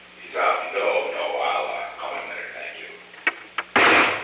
Secret White House Tapes
Conversation No. 735-12
Location: Oval Office
The President talked with an unknown person.